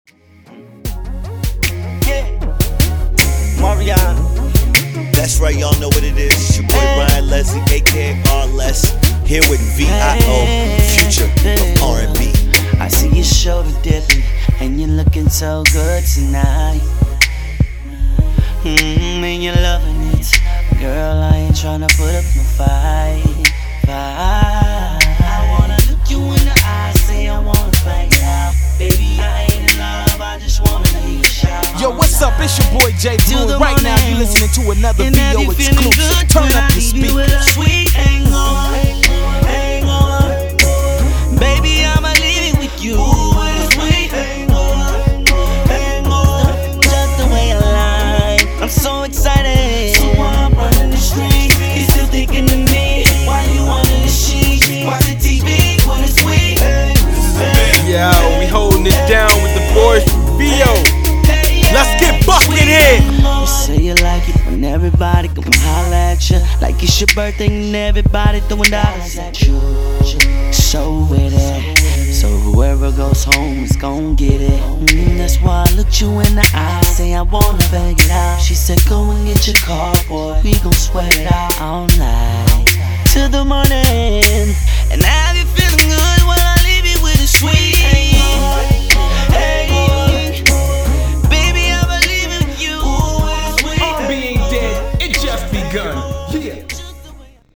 R&B
exclusive snippet